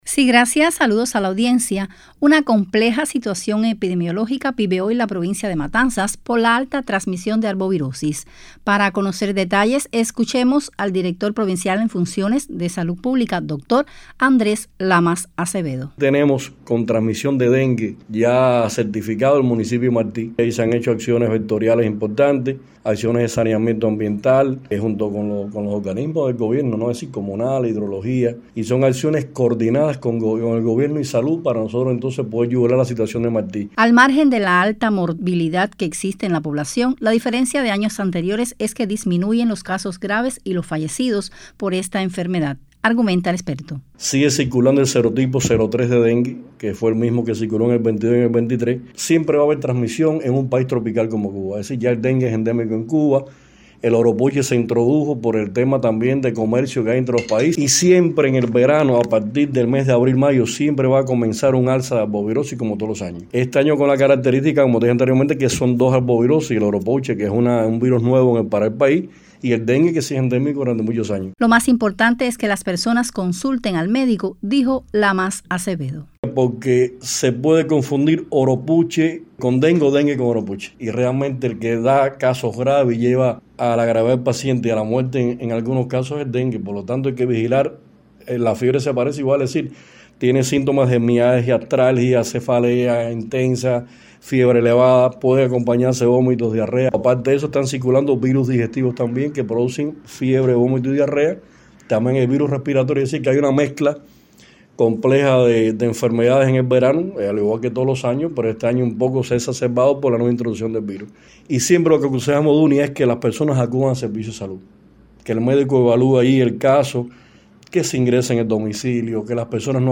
Para conocer detalles dialogamos con el director provincial, en funciones, de Salud Pública, doctor Andrés Lamas Acevedo.